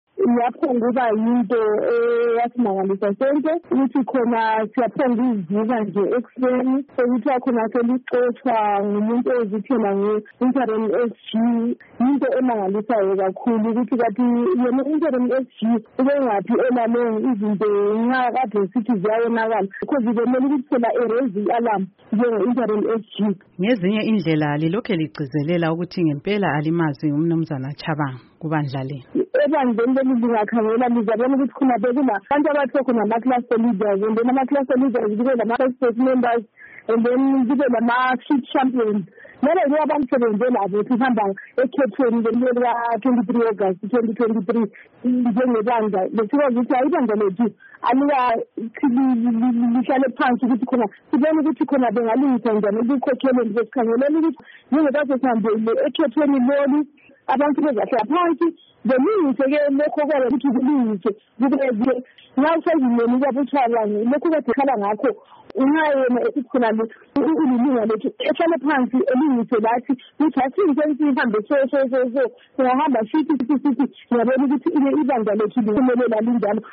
Ingxoxo loNkosazana Sithabile Mlilo